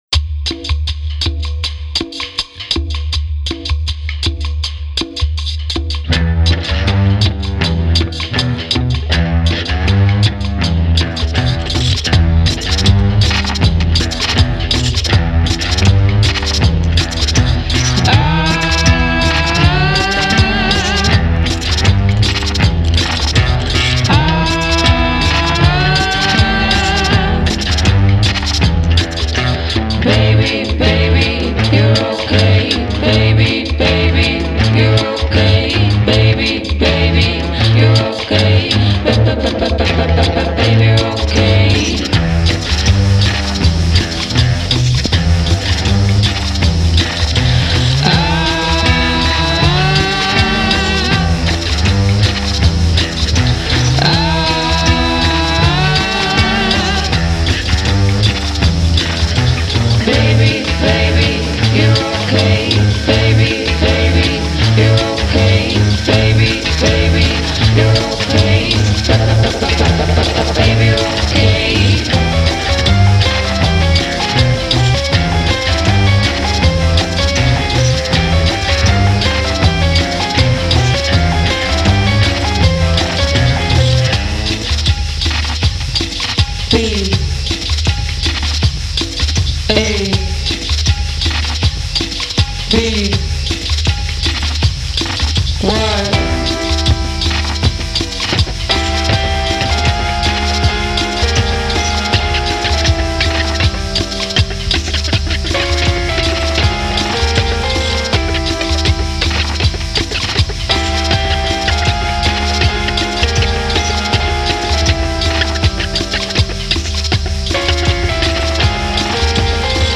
DARK COUNTRY and even Darker CUMBIA